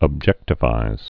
(əb-jĕktə-vīz)